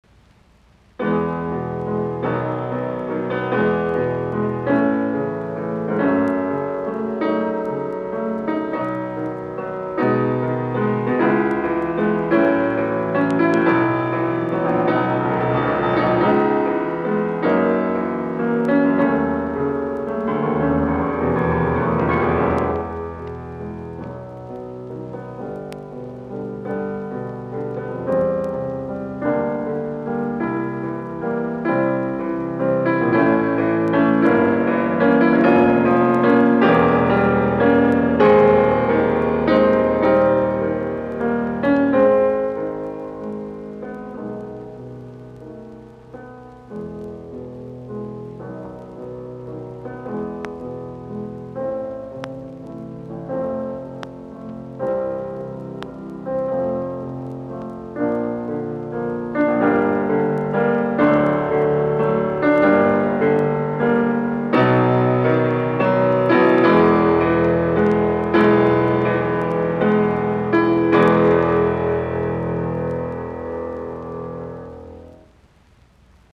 piano
No. 9 in E major, Largo